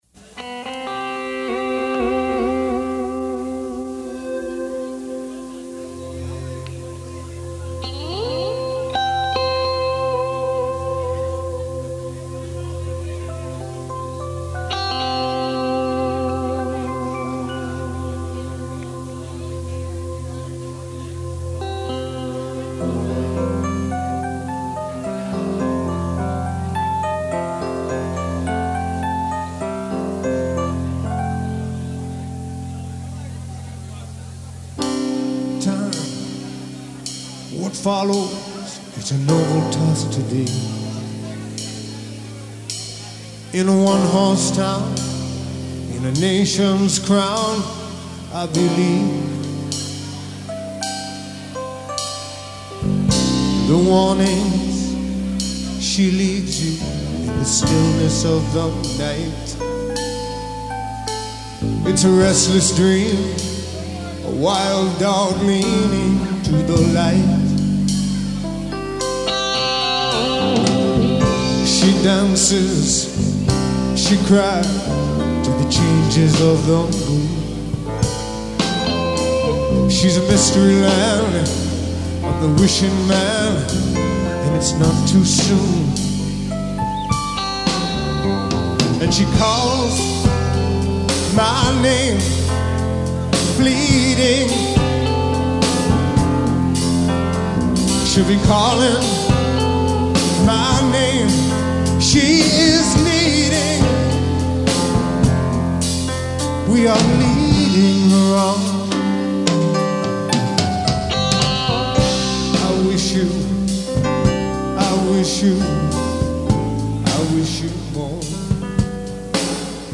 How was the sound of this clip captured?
The Live stuff